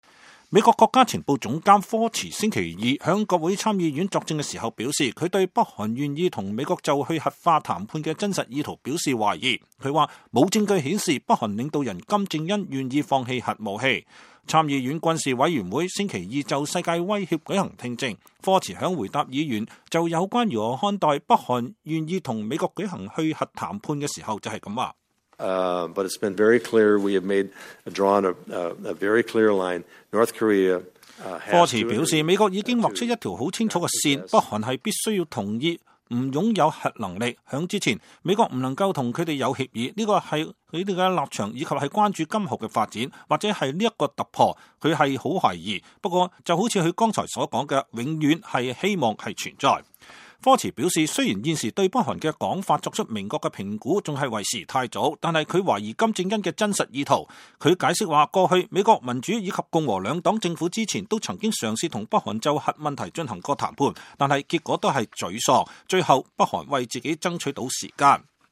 美國國家情報總監丹·科茨6號在國會作證